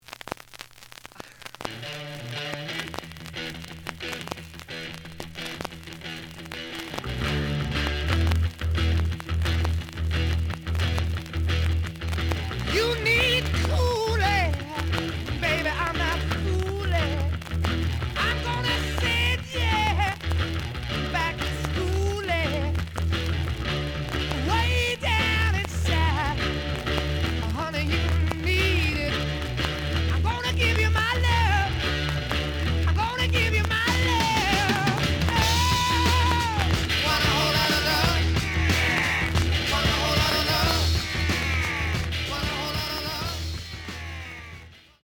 試聴は実際のレコードから録音しています。
●Genre: Rock / Pop
A面ノイジー。